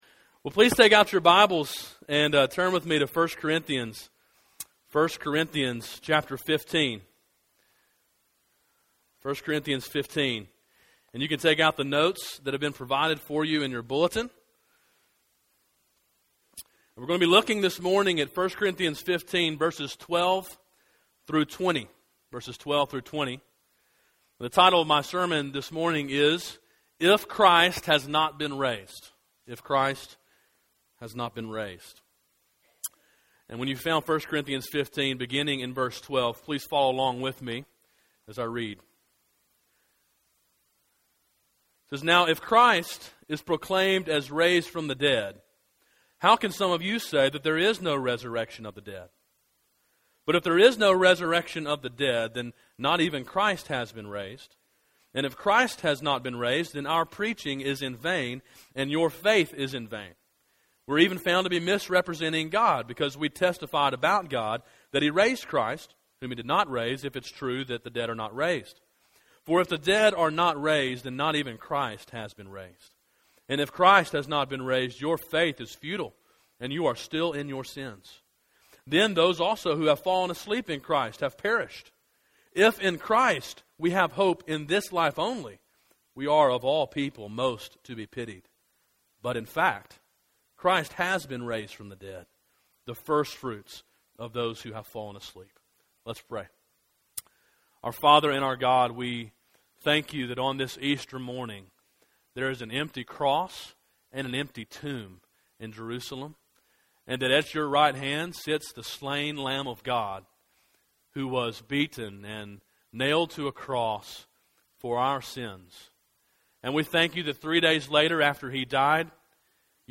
An Easter sermon, preached on 3.31.13. Download mp3